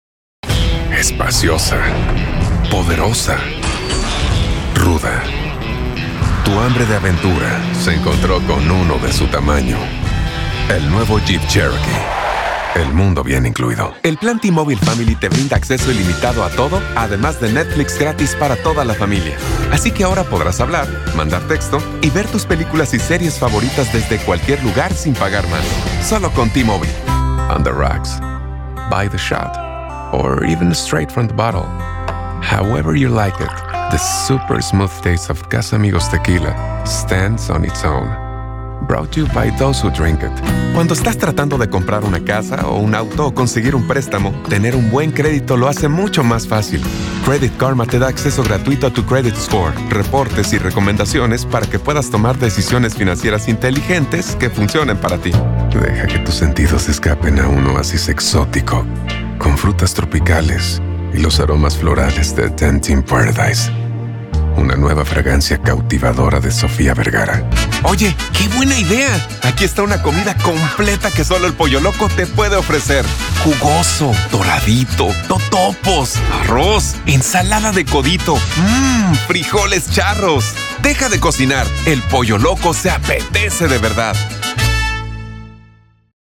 Commercial Reel (Spanish)